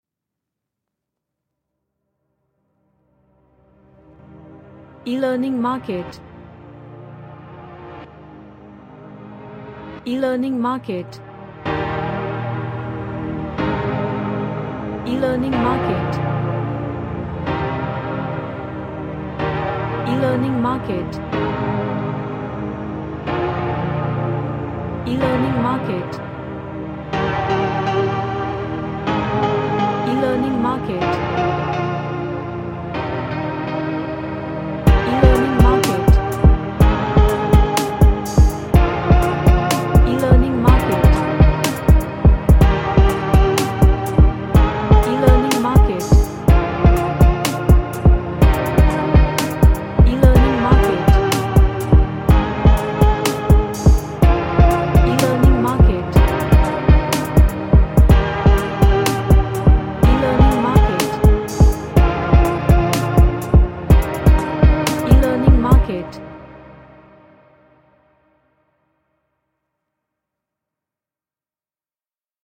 A trap track with reverse chords and vintage melody.
Sad / Nostalgic